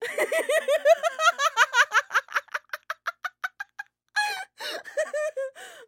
Звуки женского смеха
От чистого сердца смеётся